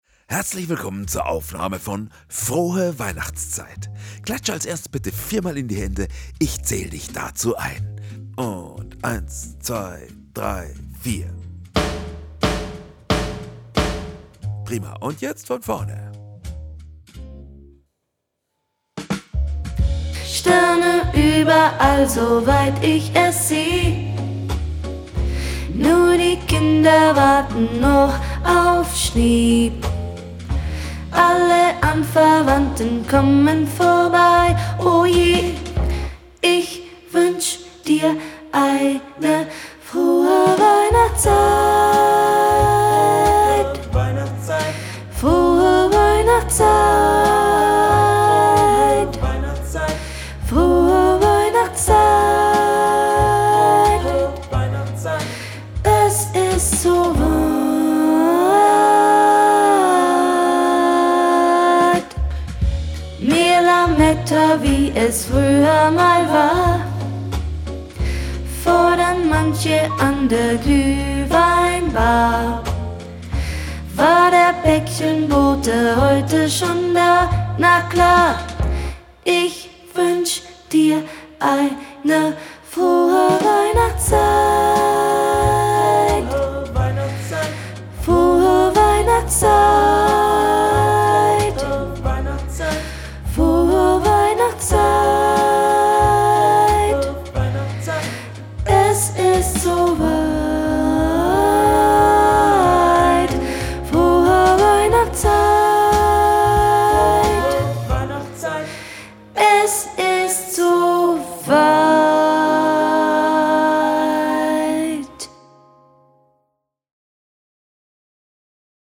Playback für Video - Sopran
Frohe_Weihnachtszeit - Playback fuer Video - Sopran.mp3